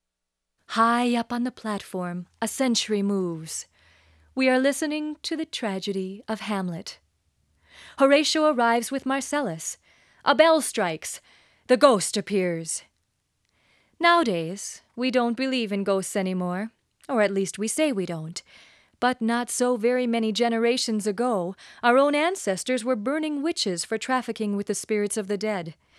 voice_clean.wav